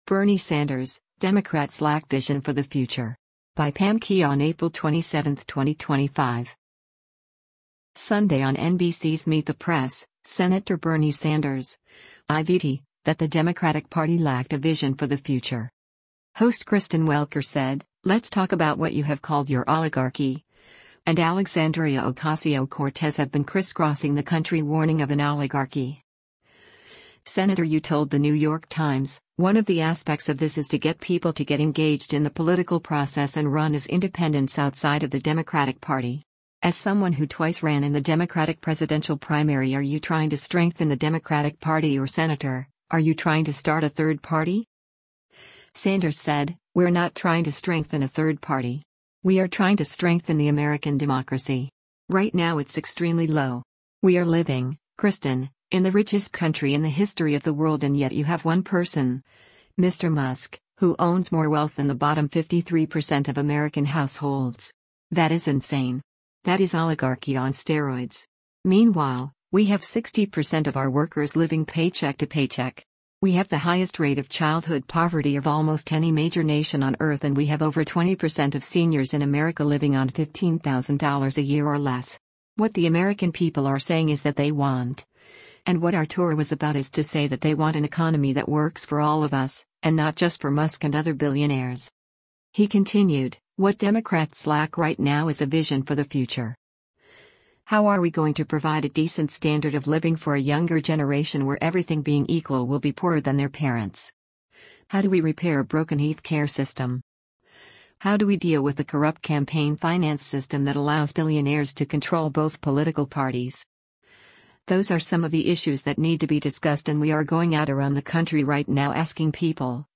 Sunday on NBC’s “Meet the Press,” Sen. Bernie Sanders (I-VT) that the Democratic Party lacked a vision for the future.